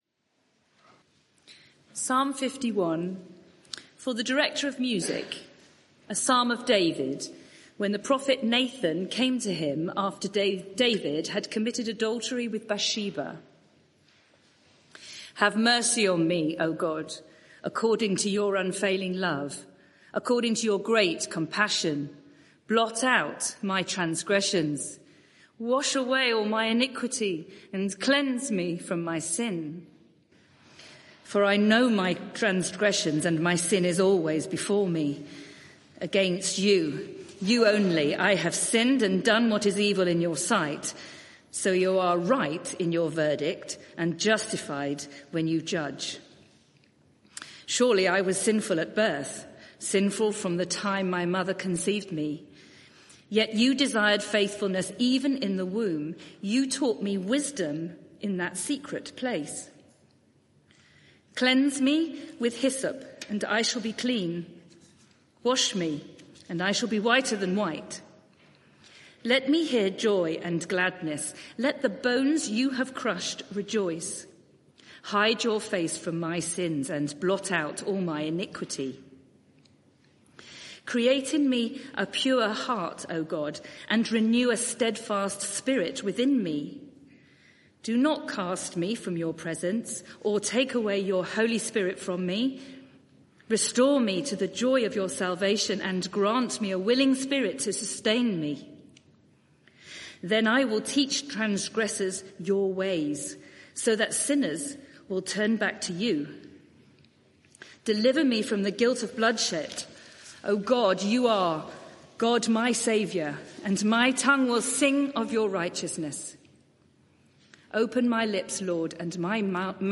Media for 6:30pm Service on Sun 25th May 2025 18:30 Speaker
Sermon (audio) Search the media library There are recordings here going back several years.